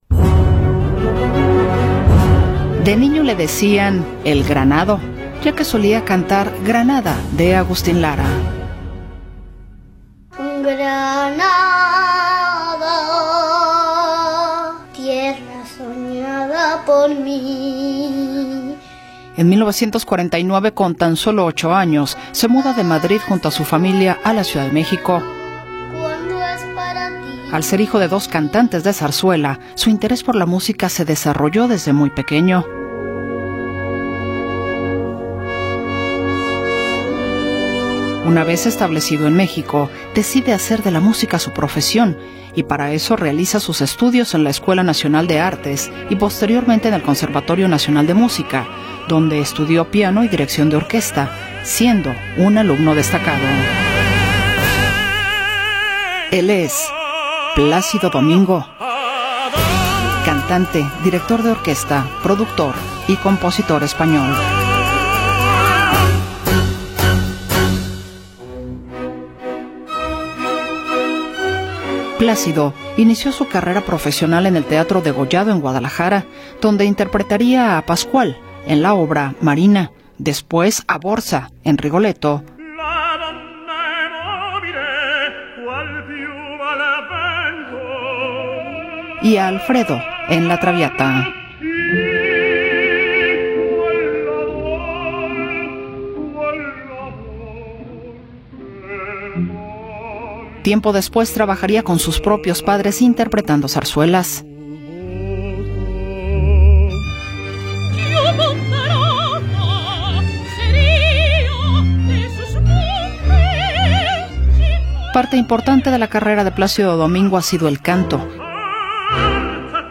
Plácido Domingo, nacido el 21 de enero de 1941 en Madrid, España, es uno de los tenores más reconocidos y versátiles de la ópera mundial.
Es conocido por su poderosa voz, su interpretación apasionada y su habilidad para conectar con el público. Fue parte del famoso trío Los Tres Tenores, junto a Luciano Pavarotti y José Carreras, cuya popularidad llevó la ópera a audiencias masivas.